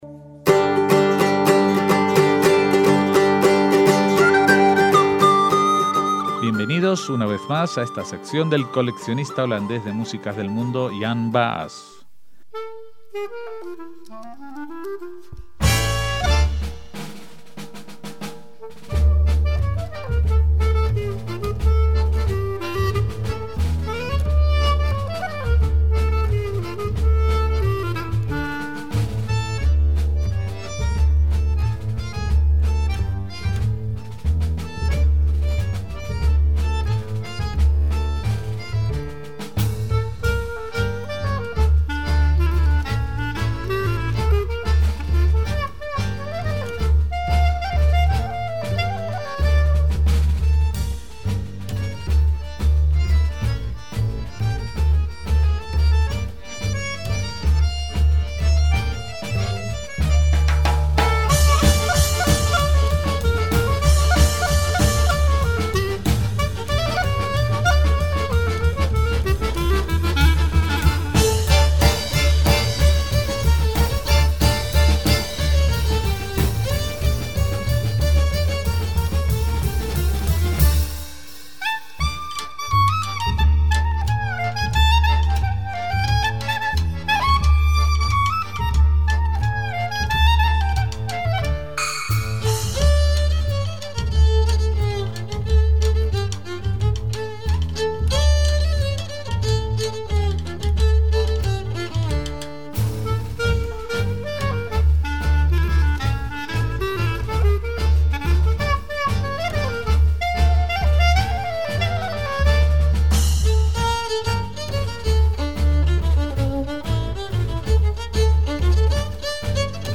Esta vez, comenzamos la emisión de programas dedicados a las diferentes ediciones del Festival de Música Judía que tiene lugar en Ámsterdam desde 2008.